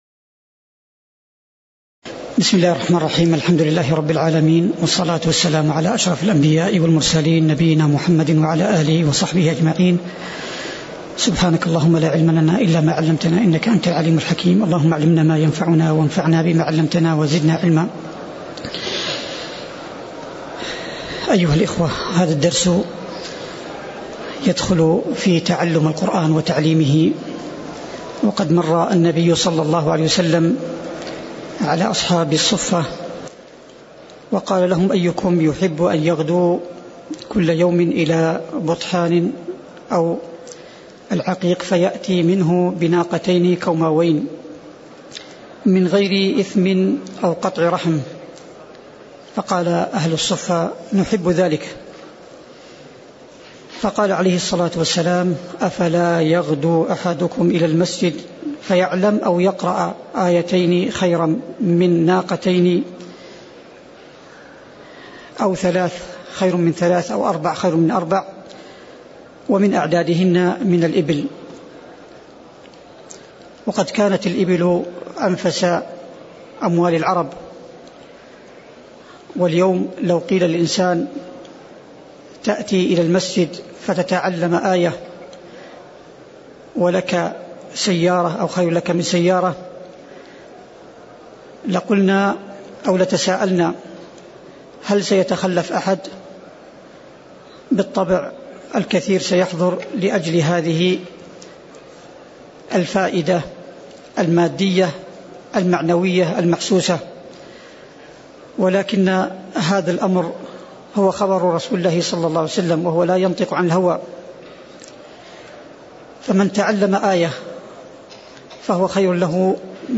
تاريخ النشر ١٩ صفر ١٤٣٨ هـ المكان: المسجد النبوي الشيخ